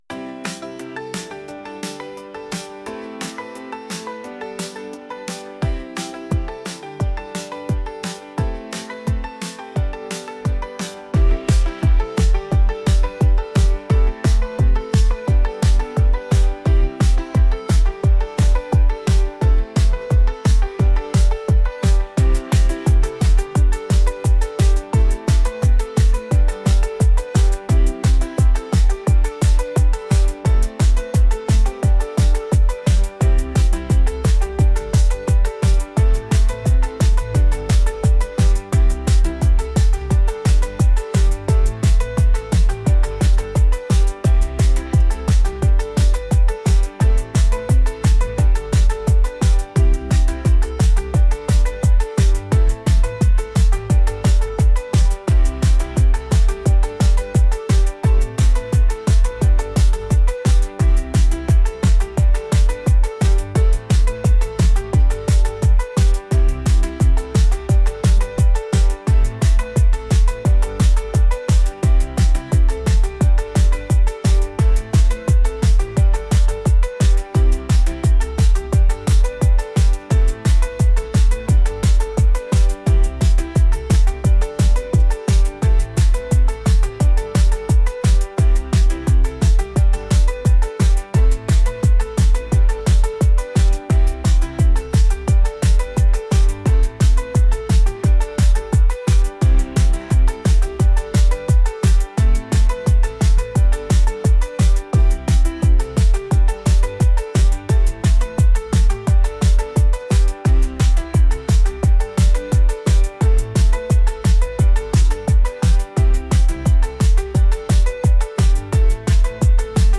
electronic | upbeat | pop